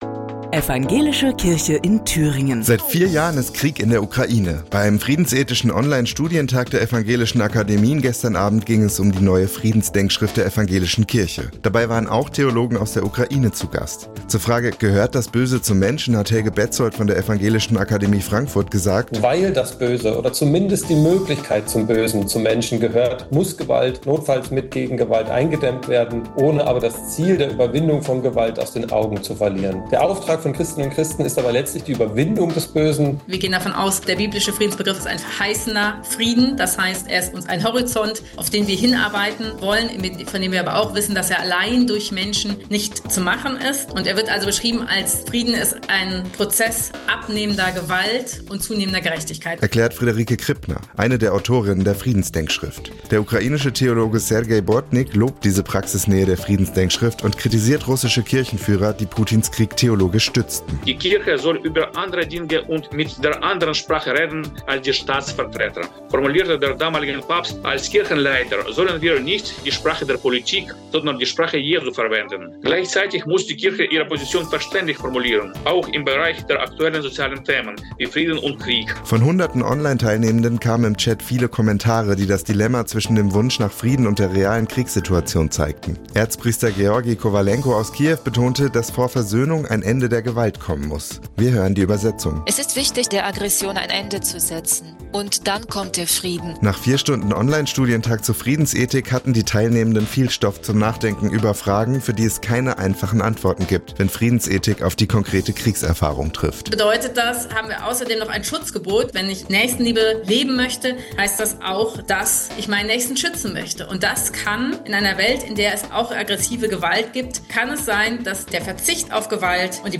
Neueste Radiobeiträge